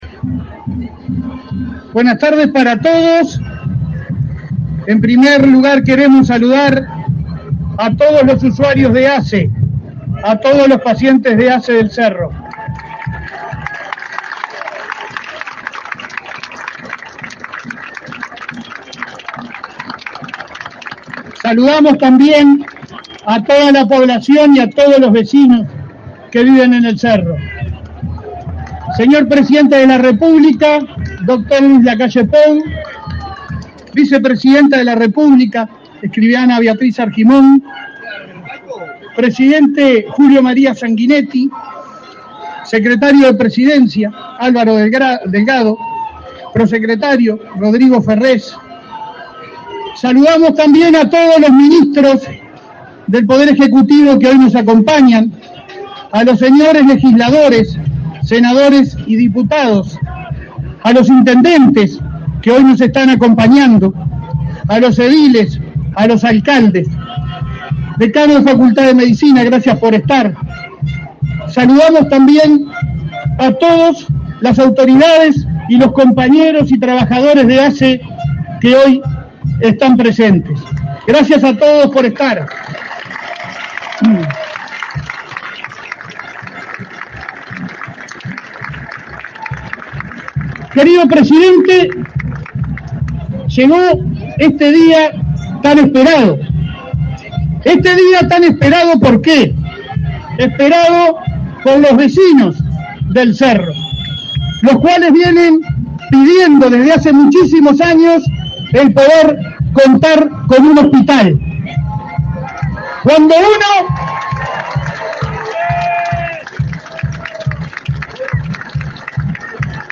Acto de inauguración del Hospital del Cerro
Con la presidencia del presidente de la República, Luis Lacalle Pou, fue inaugurado el Hospital del Cerro, este 6 de octubre.